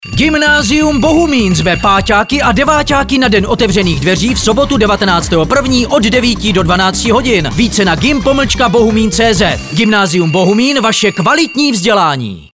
Upoutávka na den otevřených dveří, byla i v rádiu
znelka-den-otevrenych-dveri.mp3